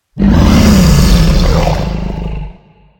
Minecraft Version Minecraft Version 25w18a Latest Release | Latest Snapshot 25w18a / assets / minecraft / sounds / mob / enderdragon / growl3.ogg Compare With Compare With Latest Release | Latest Snapshot
growl3.ogg